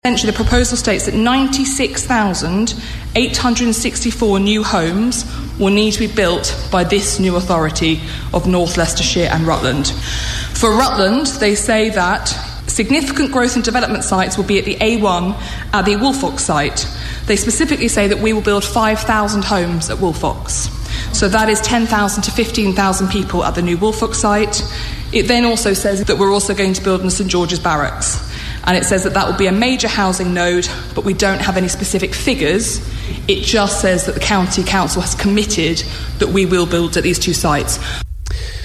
In Alicia Kearns meeting at Victoria Hall in Oakham.